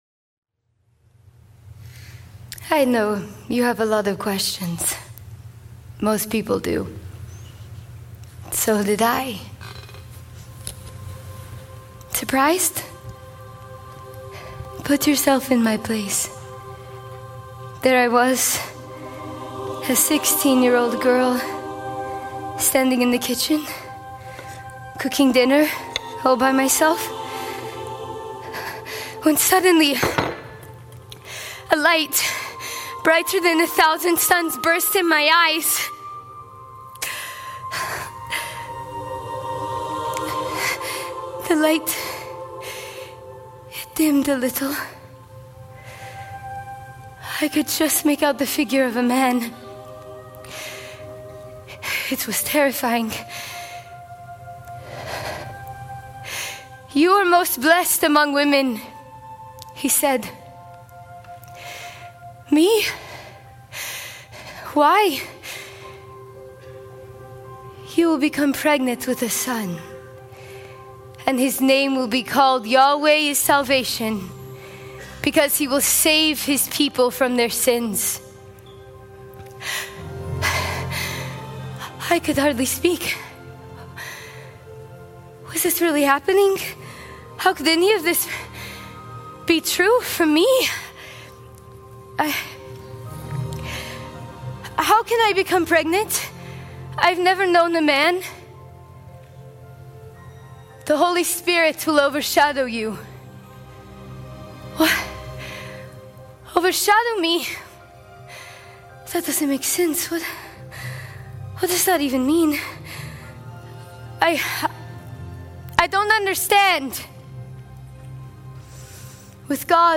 Mary Mom Monologue